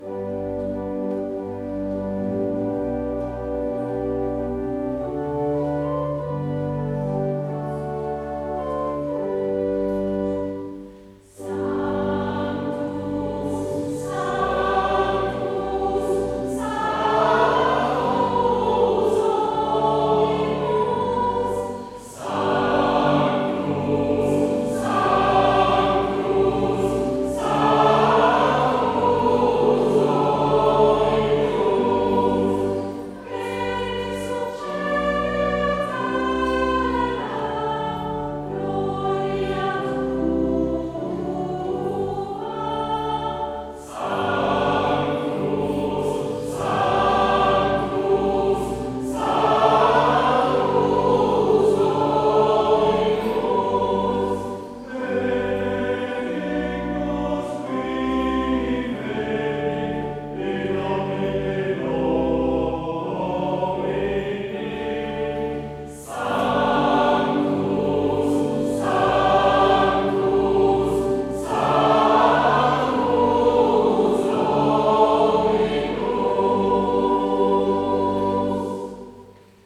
Heropening Sint-Pieterskerk Rotselaar